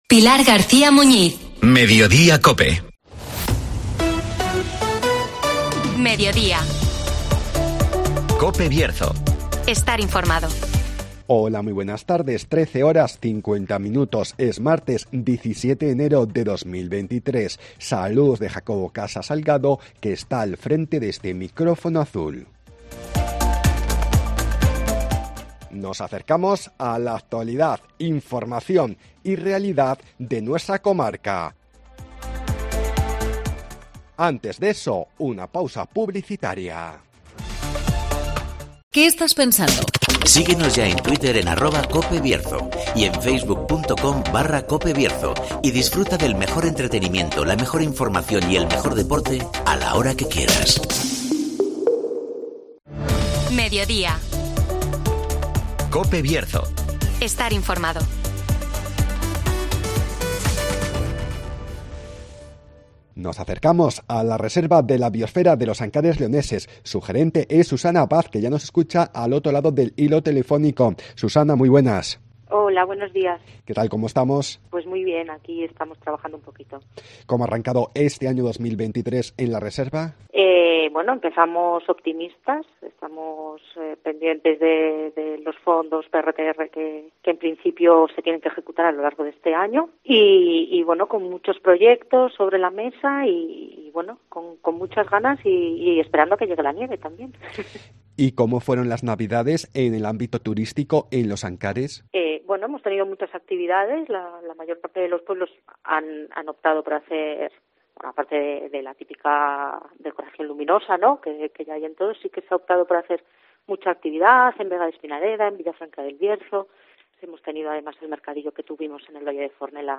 Nos acercamos a la Reserva de la Biosfera de los Ancares Leoneses (Entrevista